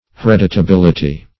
Meaning of hereditability. hereditability synonyms, pronunciation, spelling and more from Free Dictionary.
hereditability.mp3